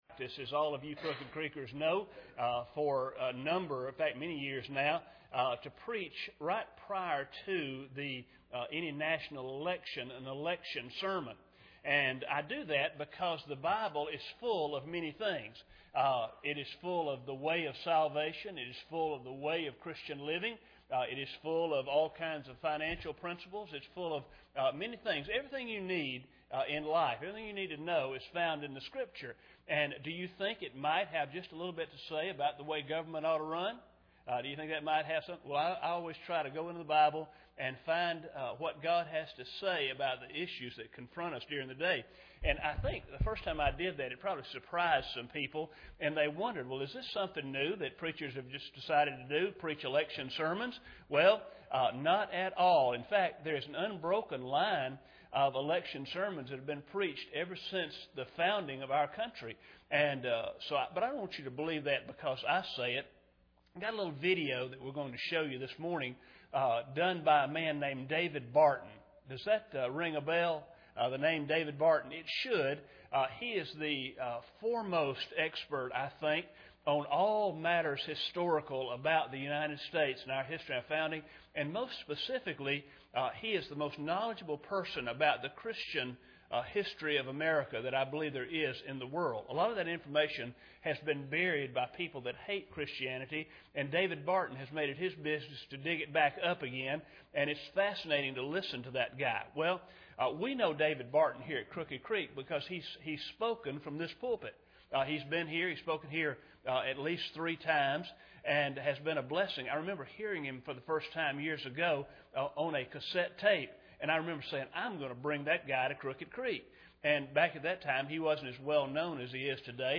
ElectionSermon2010.mp3